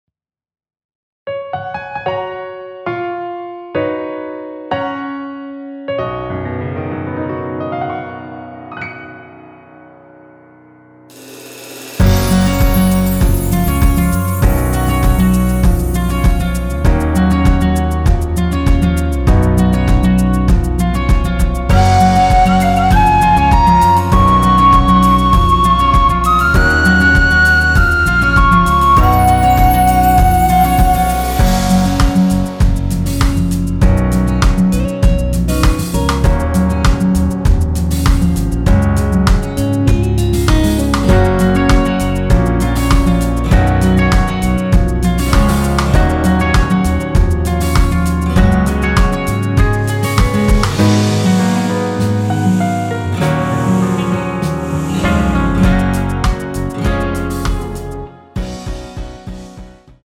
원키에서(-2)내린(1절삭제) MR입니다.
F#
앞부분30초, 뒷부분30초씩 편집해서 올려 드리고 있습니다.